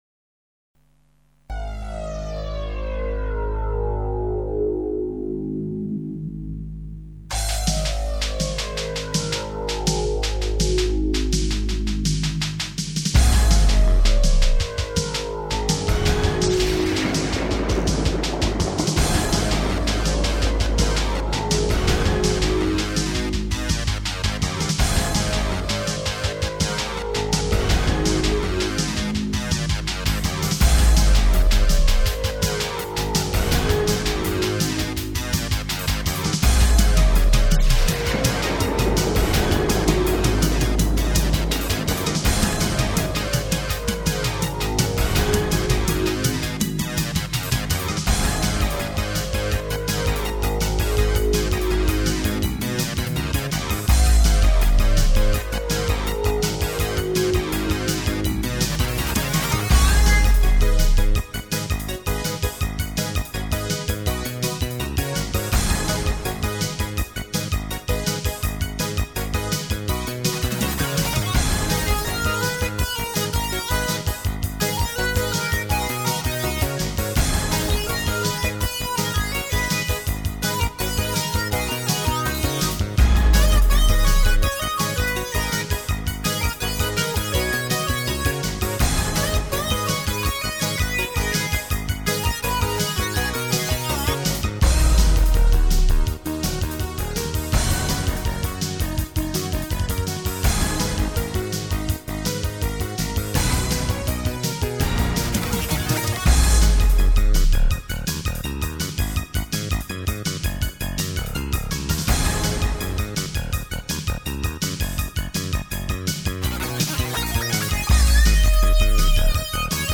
Även på denna låt är det MS2000 på melodi. "Elgitarrsolot" i slutet är JP-8000: